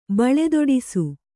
♪ baḷedoḍisu